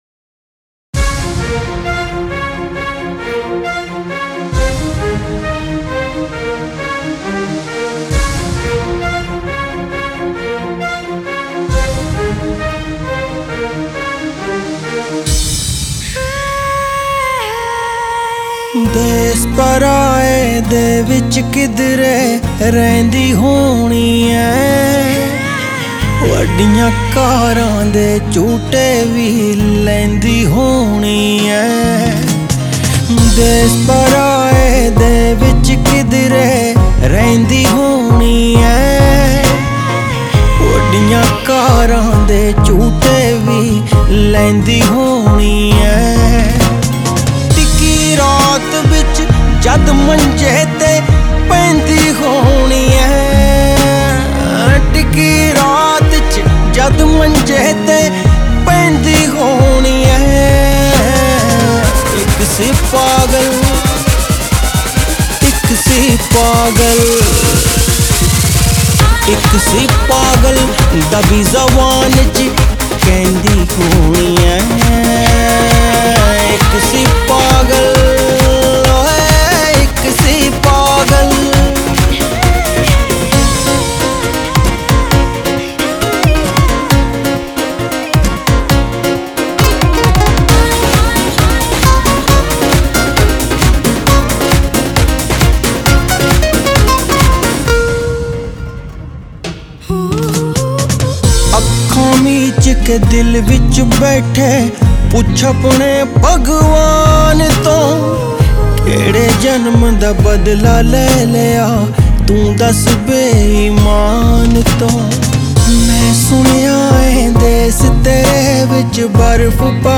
آهنگ محلی شاد هندی
Happy Local Song of Indian